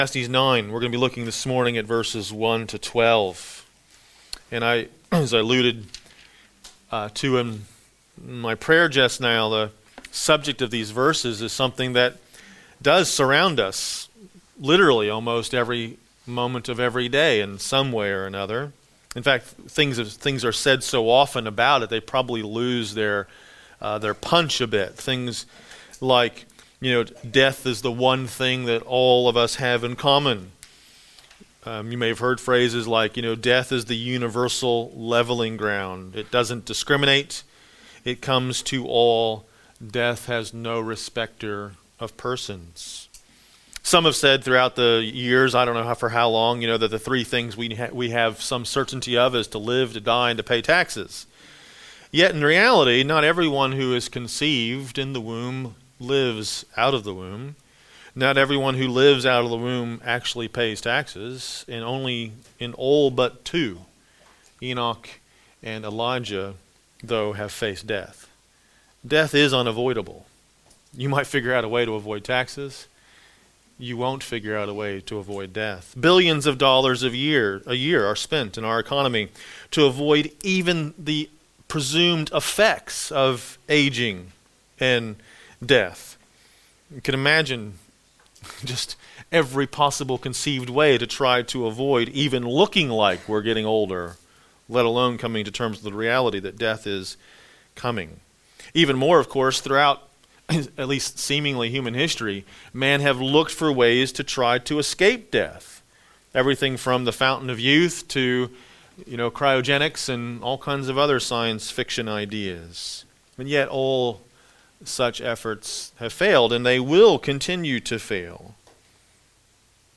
A message from the series "Isaiah."